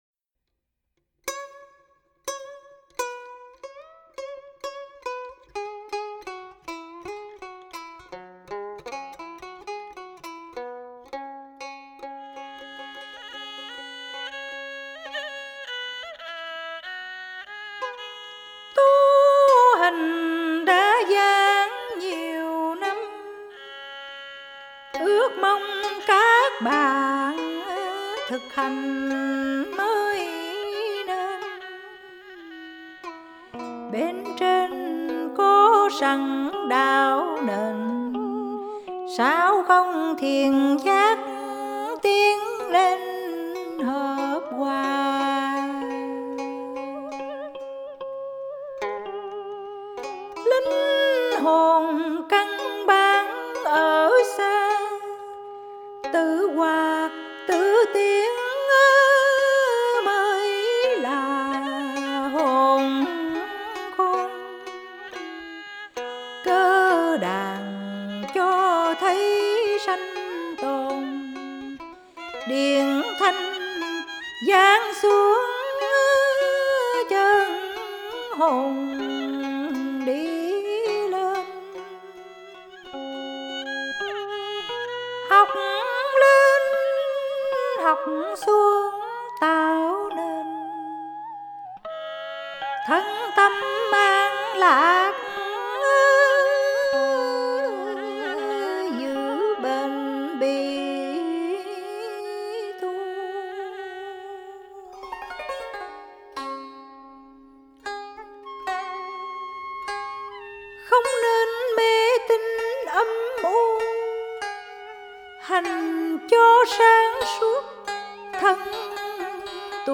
Dân Ca & Cải Lương